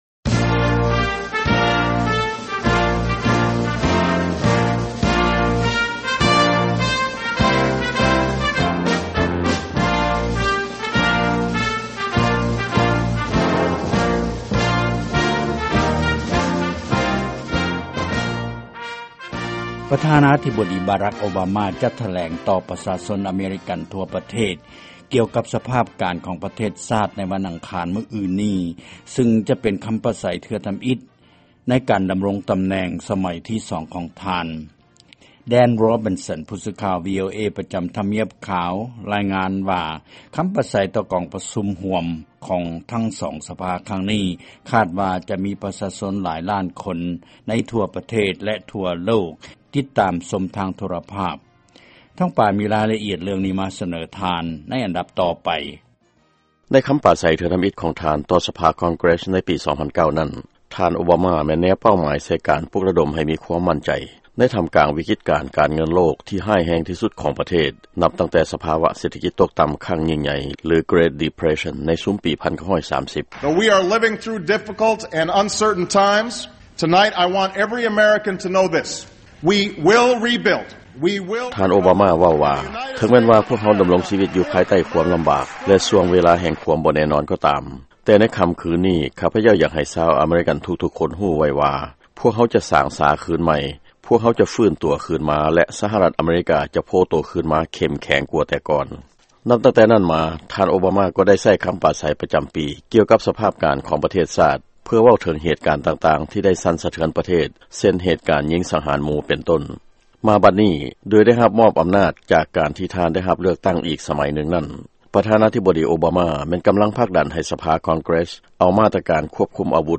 ຟັງລາຍງານ ກ່ຽວກັບ ຄໍາປາໄສຂອງທ່ານໂອບາມາ